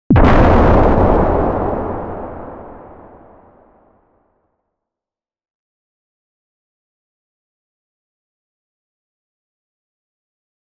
Download Bomb sound effect for free.
Bomb